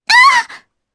Epis-Vox_Damage_jp_02.wav